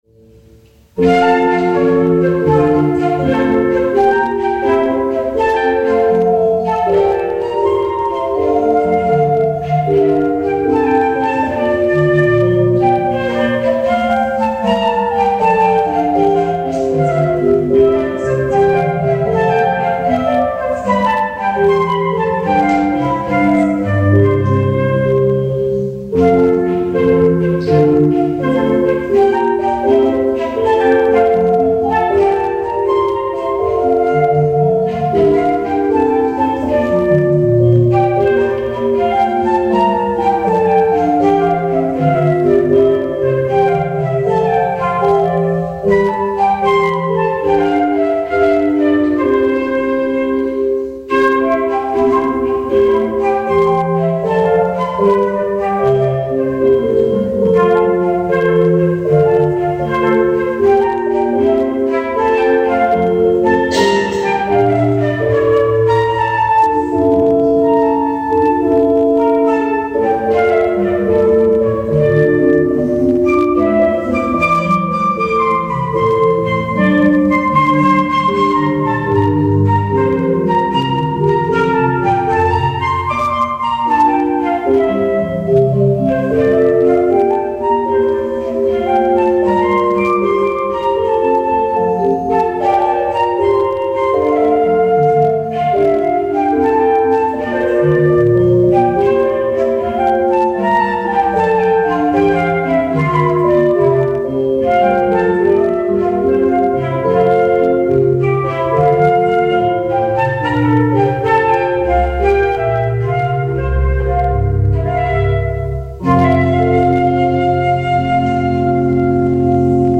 in der Erlöserkirche
Notker Wolf musizierte an der Querflöte
Orgel
Hörbeispiel 2 der schnelle Satz + Applaus].